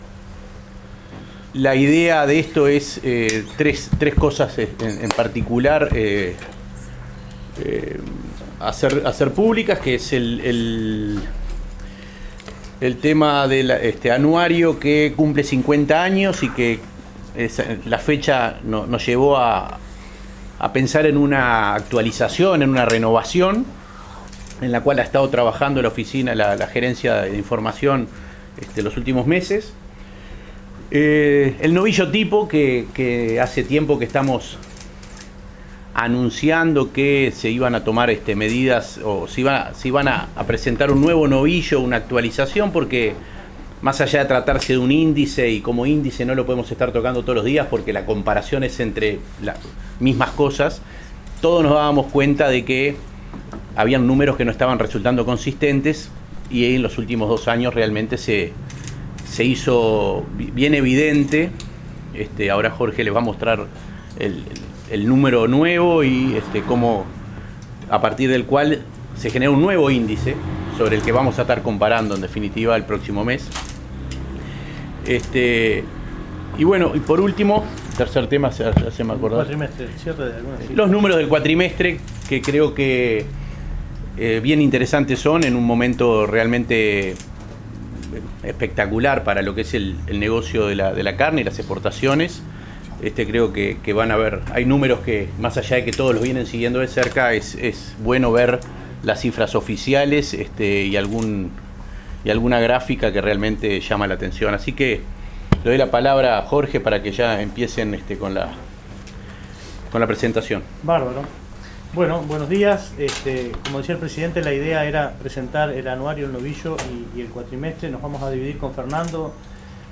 Durante la conferencia de prensa se visualizó el nuevo formato del Anuario Estadístico, que tiene la opción de ser interactivo, incluye por primera vez información sobre consumo de carnes en el Uruguay.
conferencia.mp3